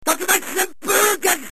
sfm_mp3_street_fighter_tatsumaki_senpuu_kyaku_sound_effect.mp3